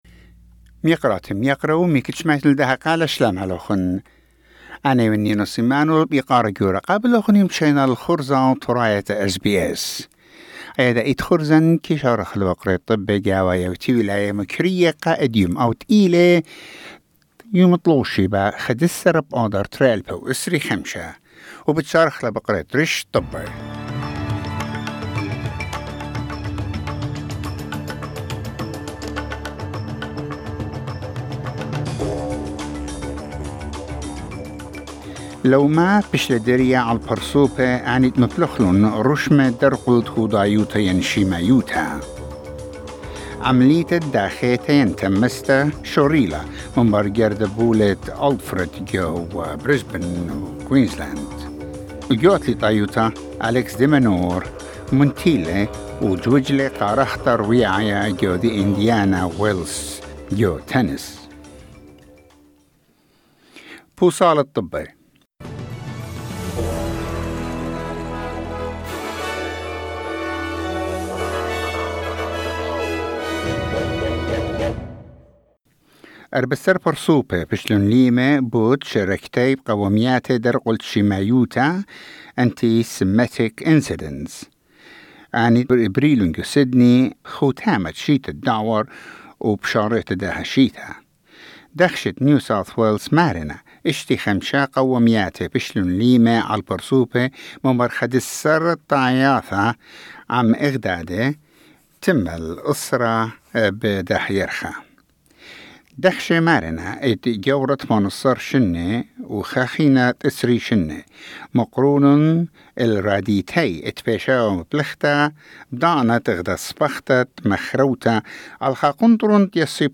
SBS Assyrian news bulletin: 11 March 2025